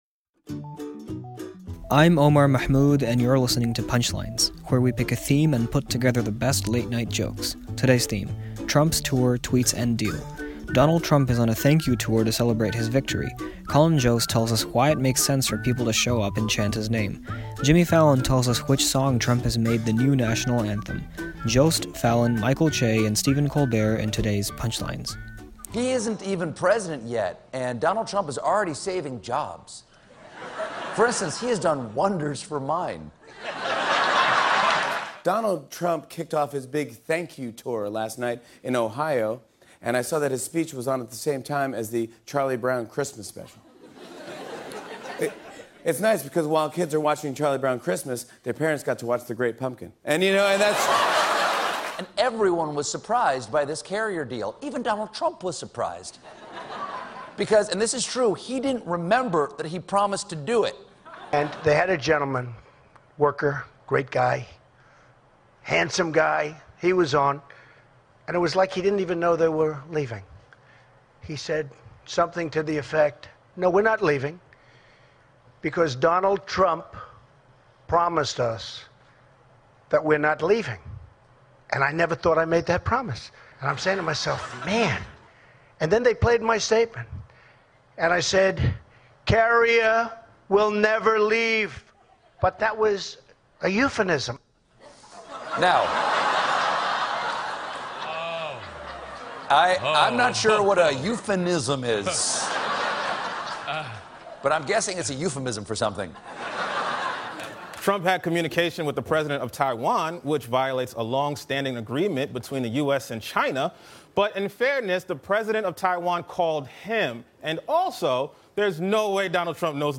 Take a look at what the late-night comics have to say on the latest from Trump.